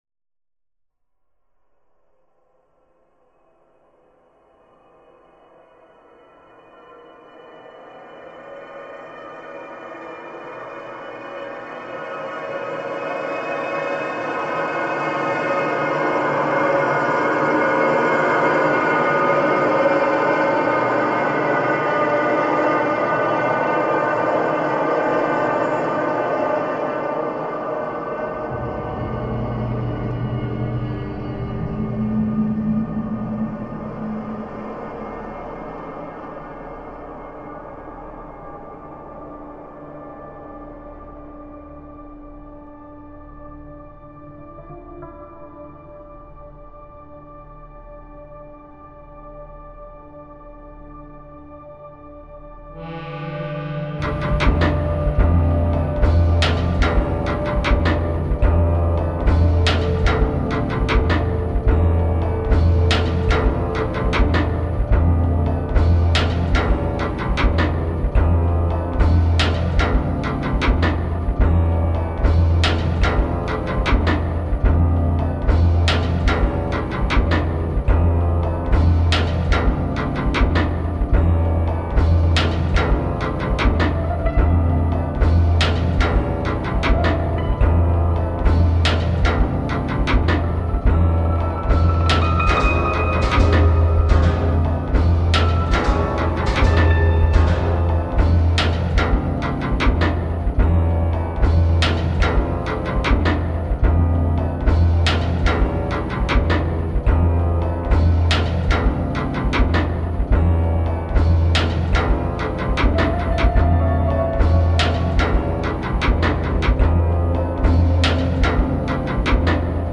melancholy and dramatic power
dark/bombastic industrial scene